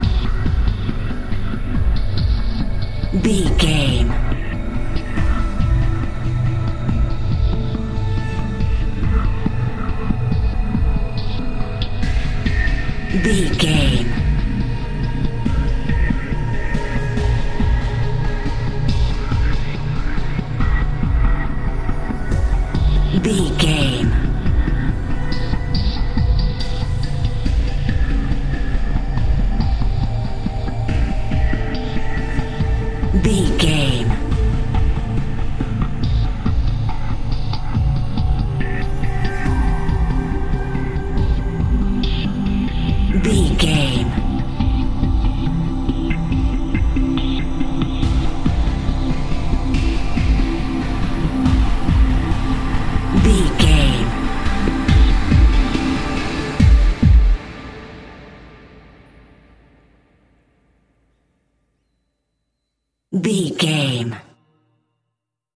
Ionian/Major
D
synthesiser
drum machine
dark
haunting